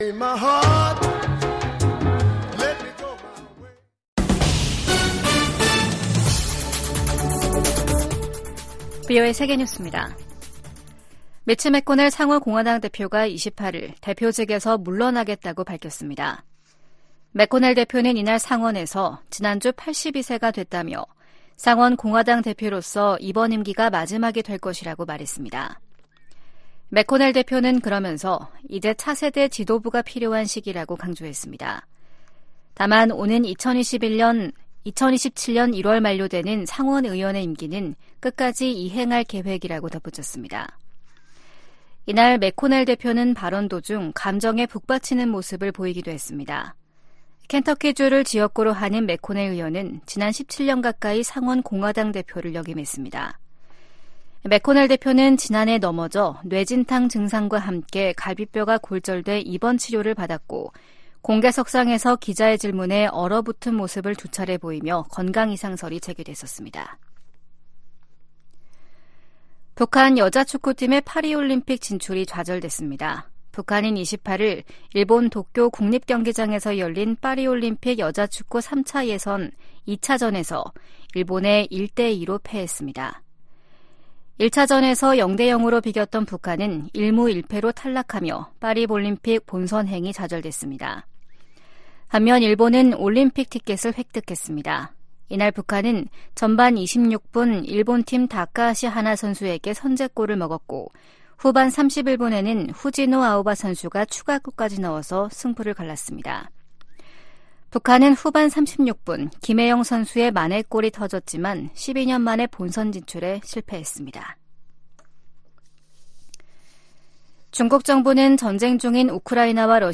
VOA 한국어 아침 뉴스 프로그램 '워싱턴 뉴스 광장' 2024년 2월 29일 방송입니다.